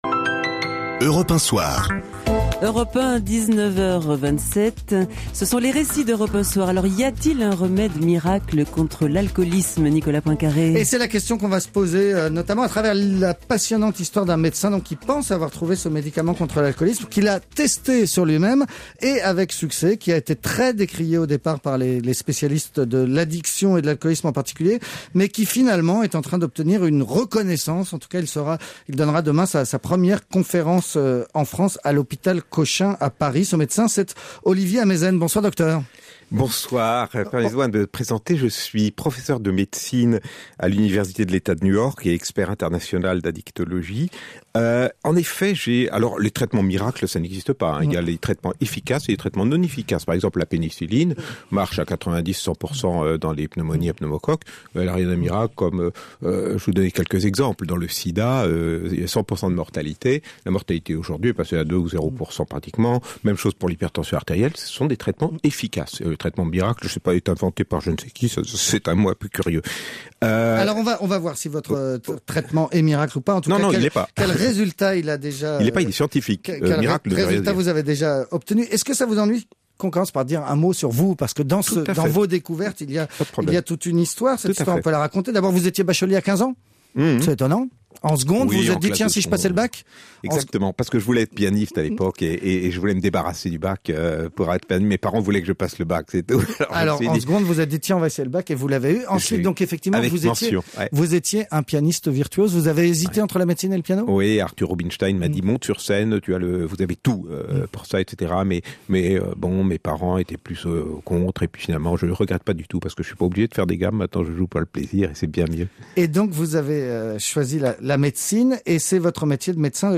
Baclofène – Interview Olivier Ameisen – Europe1 soir du 24/01/2011
Europe 1 soir – Nicolas POINCARE – Interview Olivier Ameisen